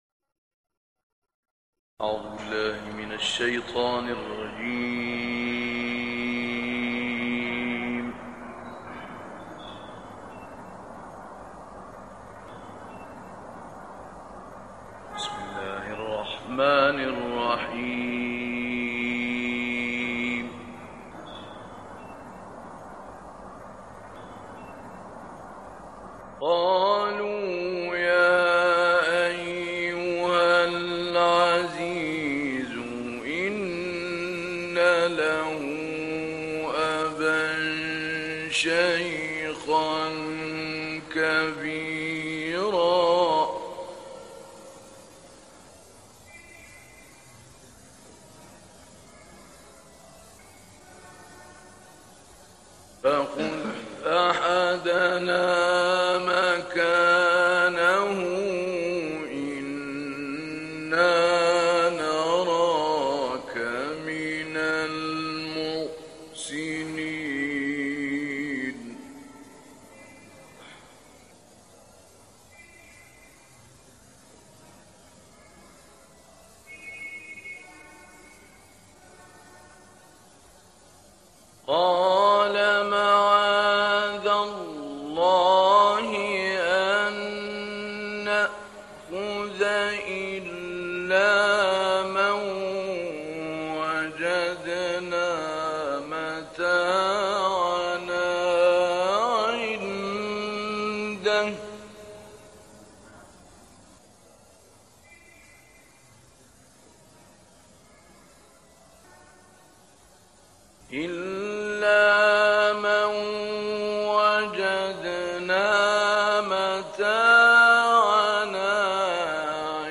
سوره یوسف با صدای محمود على البناء + دانلود/ احسن القصص؛ عبرتی برای صاحبان خرد
گروه فعالیت‌های قرآنی: قطعه‌ای از تلاوت استاد محمود علی البناء از آیات ۷۸-۱۰۱ سوره یوسف ارائه می‌شود.